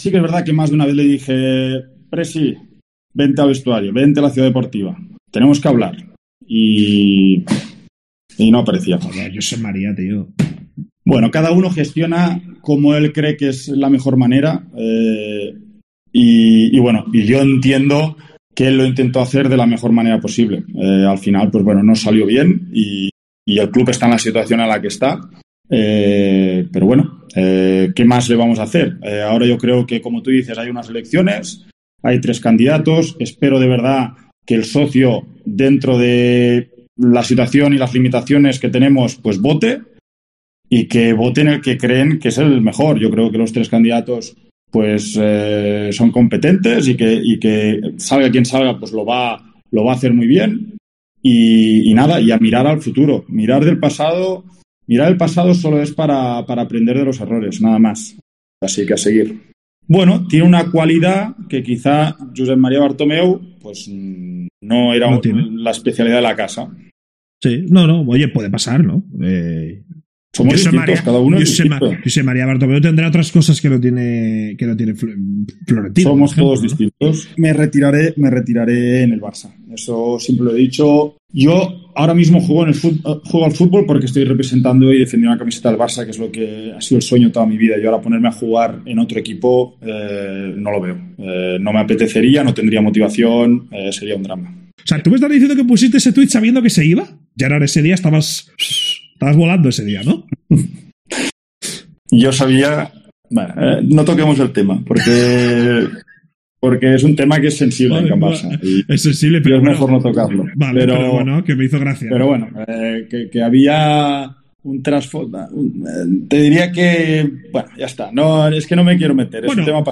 AUDIO: El futbolista del FC Barcelona Gerard Piqué habló en una entrevista concedida al "streamer" Ibai Llanos